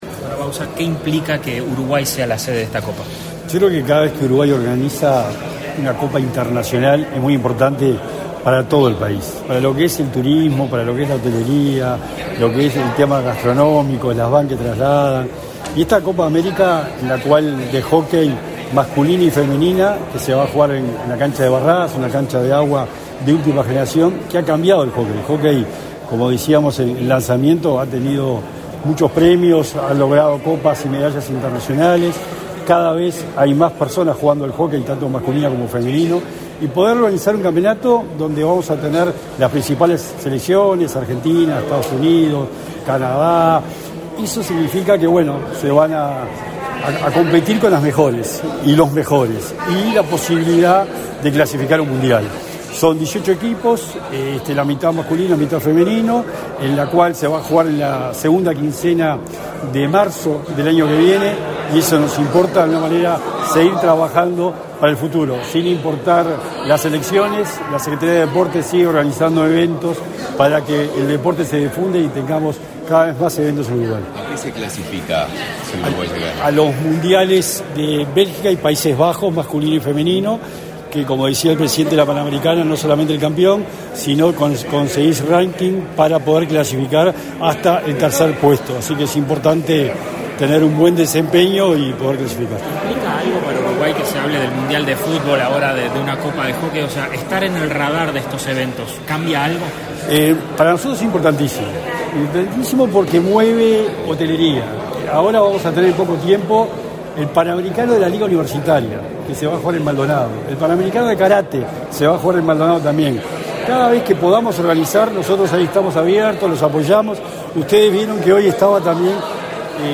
Declaraciones a la prensa del secretario nacional de Deporte, Sebastián Bauzá
Declaraciones a la prensa del secretario nacional de Deporte, Sebastián Bauzá 04/04/2024 Compartir Facebook Twitter Copiar enlace WhatsApp LinkedIn Tras participar en el lanzamiento de la Copa América de Hockey en Uruguay, este 4 de abril, el secretario nacional del Deporte, Sebastián Bauzá, realizó declaraciones a la prensa.